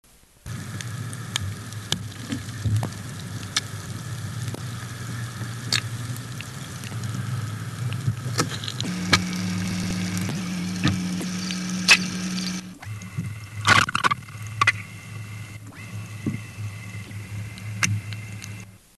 Type of sound produced clucks, clicks, squeak, thumps
Sound production organ swim bladder, pneumatic duct, possibly teeth
Sound mechanism Knocks: vibration of swim bladder & pneumatic duct, possibly by general body contraction, clucking: escape of gas bubbles from pneumatic duct; clicking: possibly teeth, associated with opening of mouth & contraction of gill region
Behavioural context spontanous sounds (noturnal, behav. not specified)
Remark recording in wooden tank, recorded with highpass filter: 100 Hz